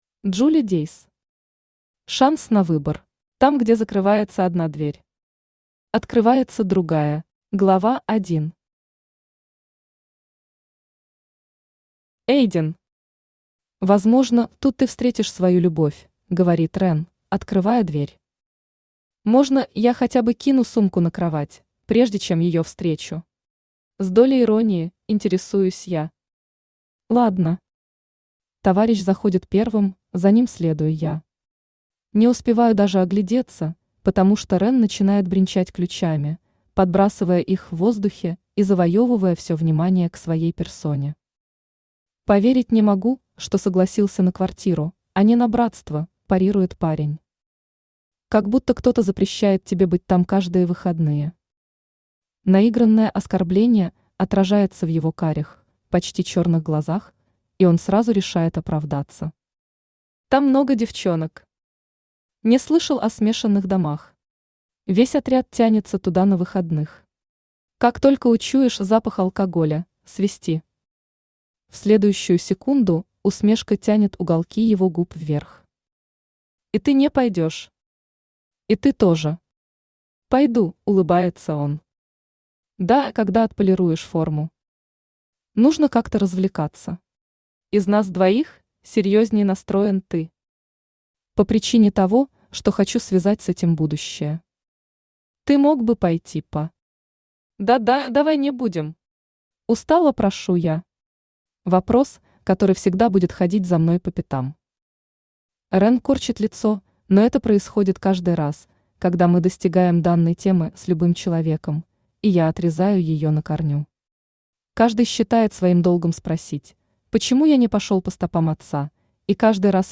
Аудиокнига Шанс на выбор | Библиотека аудиокниг
Aудиокнига Шанс на выбор Автор Джули Дейс Читает аудиокнигу Авточтец ЛитРес.